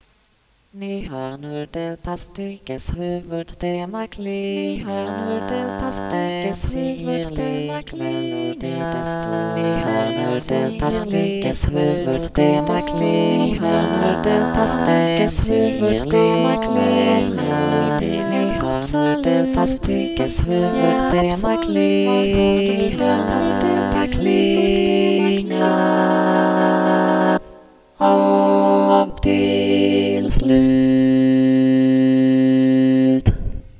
Fuga
Polyphonous, four voices Download